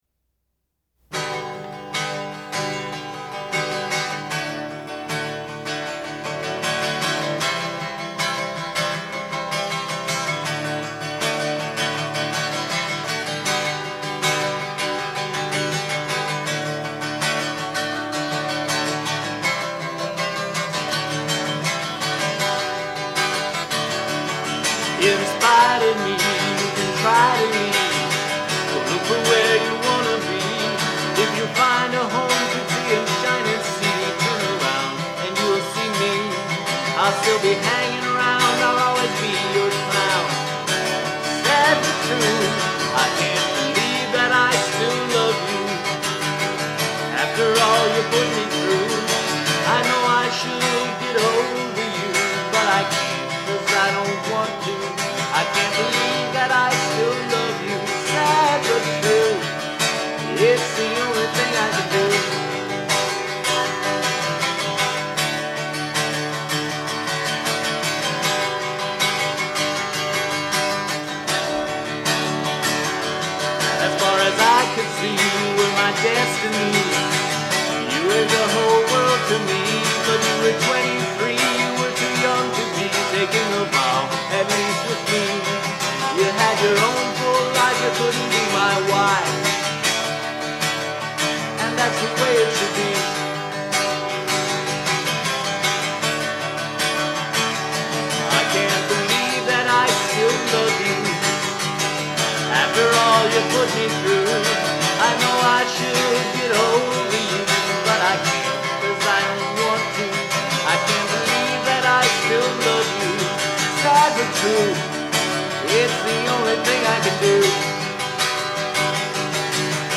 High resolution Mono
sped up.
It wound up almost a minute shorter.